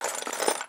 SFX_Metal Sounds_06.wav